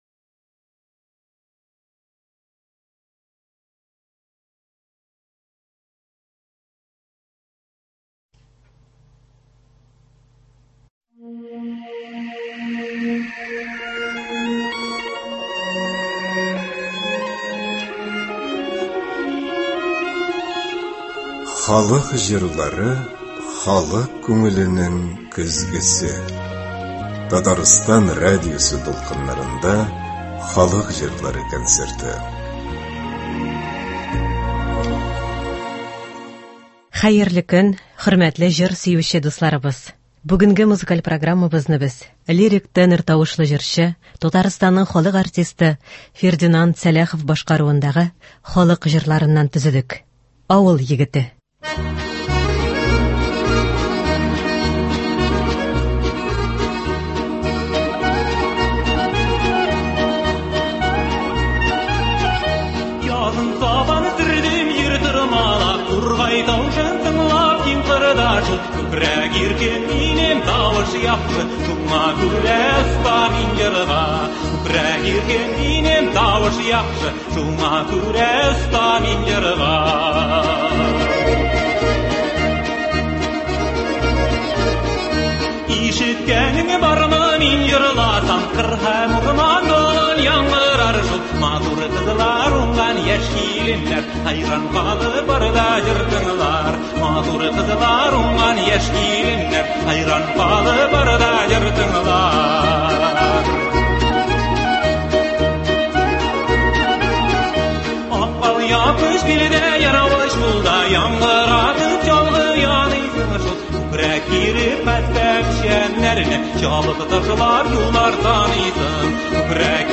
Фердинанд Сәлахов башкаруында татар халык җырлары (14.09.24)
Бүгенге музыкаль программабызны без лирик тенор тавышлы җырчы, Татарстанның халык артисты Фердинанд Сәлахов башкаруындагы халык җырларыннан төзедек.